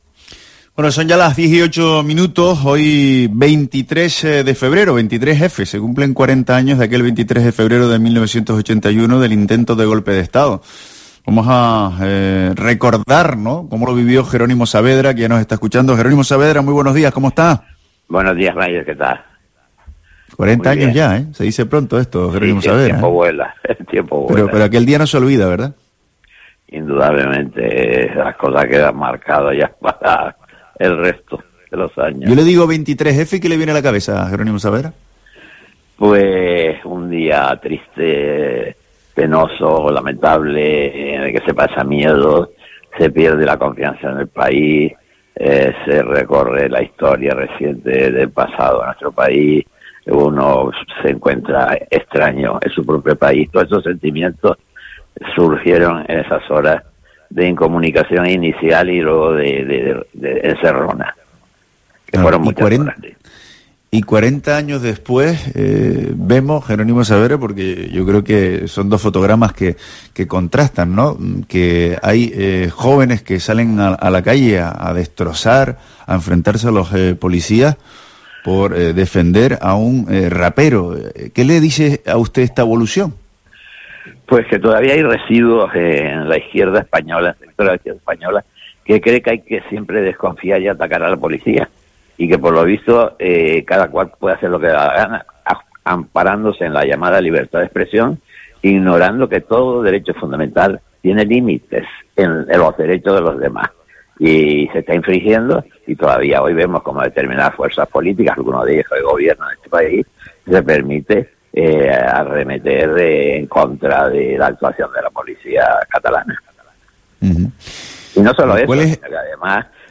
Jerónimo Saavedra , diputado socialista por Las Palmas en 1981 ha recordado en los micrófonos de COPE Canarias cómo se sintió al vivir encerrado en el Congreso de los Diputados el intento de Golpe de Estado hace 40 años.